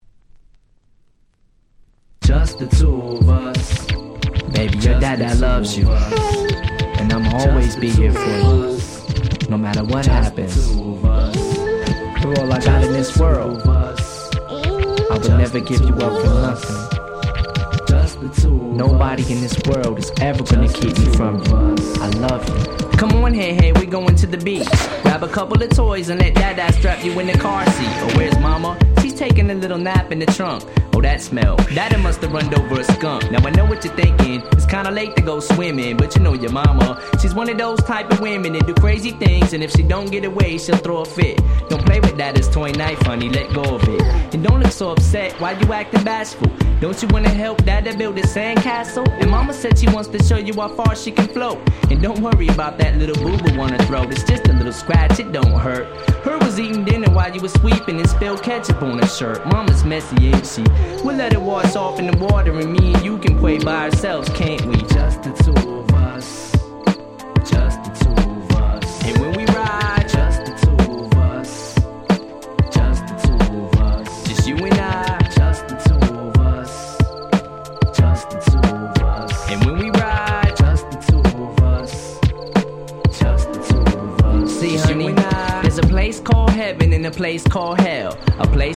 90's Boom Bap ブーンバップ